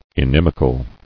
[in·im·i·cal]